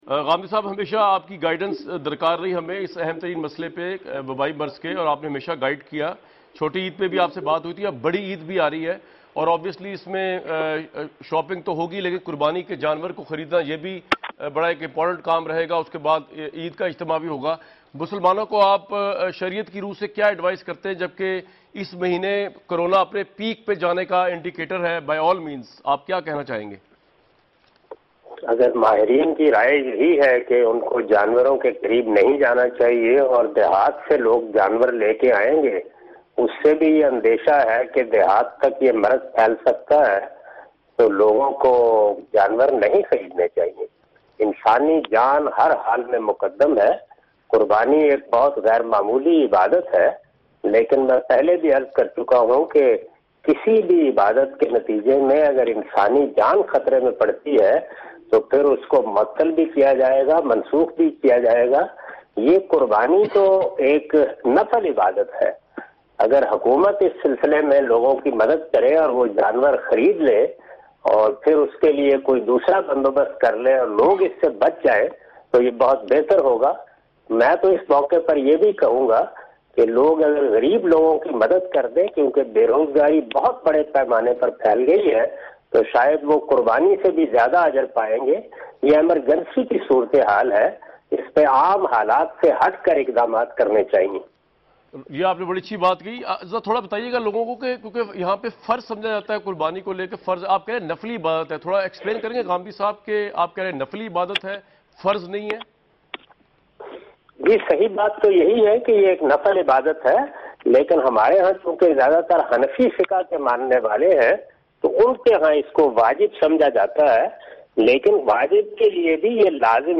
Category: TV Programs / Dunya News / Questions_Answers /
In this video, Mr Ghamidi answers the questions about "Corornavirus: Important questions about Eid al-Azha and Animal Sacrifice".